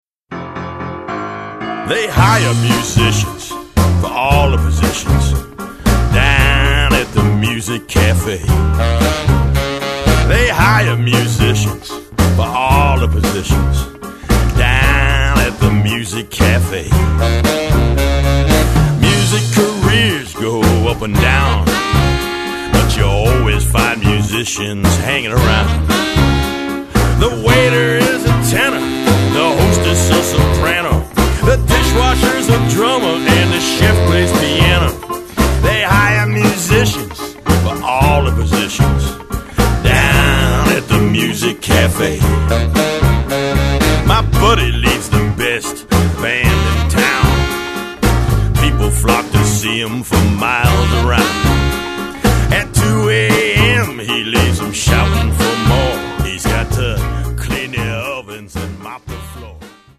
He has been playing the piano since the age of 5.